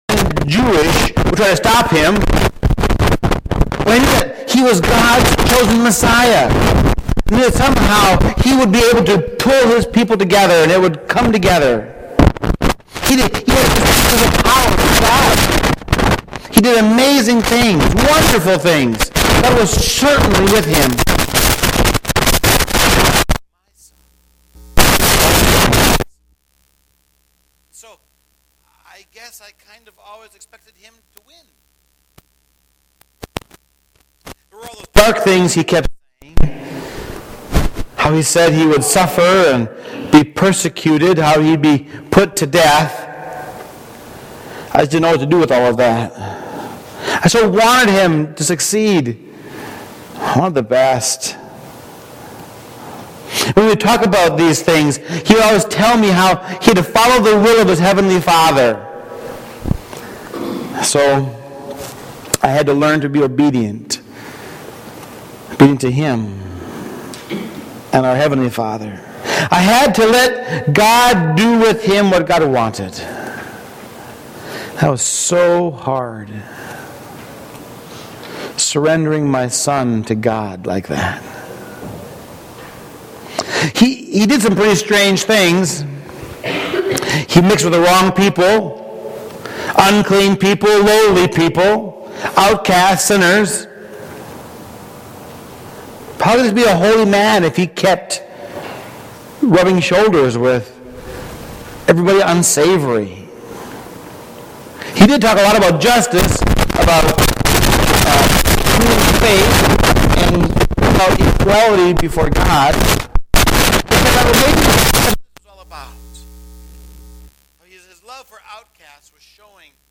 Sermons Archive - Page 25 of 29 - Forest Hills Church